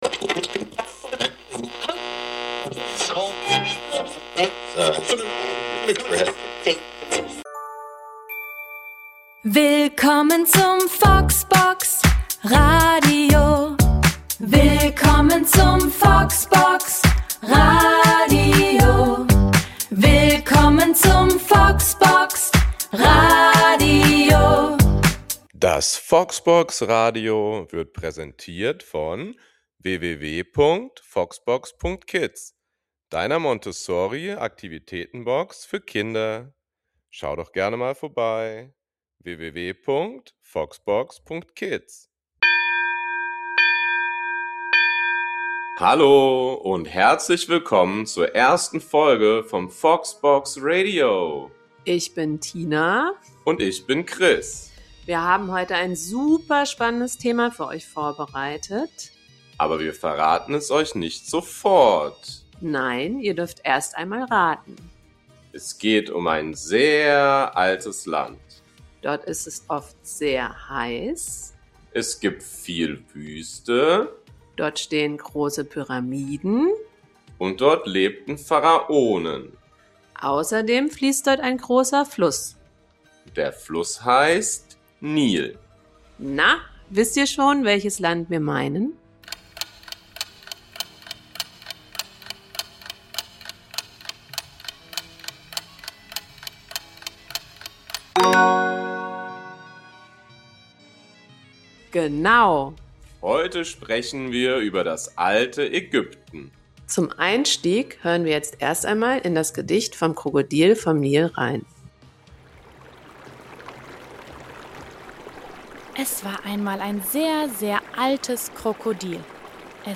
Beschreibung vor 1 Tag In der ersten Folge des FoxBox-Radios reisen kleine Entdecker ins Alte Ägypten. Kinder im Kindergartenalter erfahren auf einfache und spielerische Weise Spannendes über den Nil, die Pyramiden und die Pharaonen. Mit einer Geschichte über einen jungen Pharao, zwei Liedern und einem kleinen Quiz zum Schluss wird Wissen kindgerecht, lebendig und mit viel Freude vermittelt.